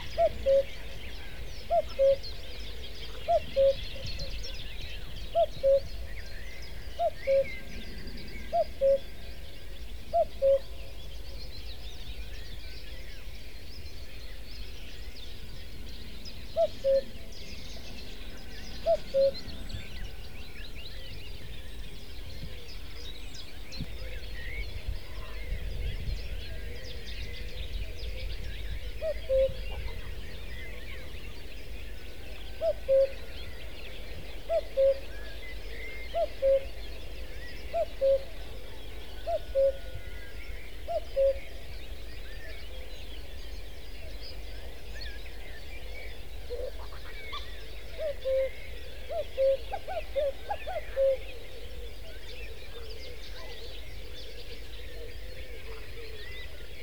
Le fameux « ku koo » caractéristique du Coucou gris qui a été repris pour rappeler l’heure sur les anciennes horloges vient de cet oiseau qui porte bien son nom.
Chant du Coucou gris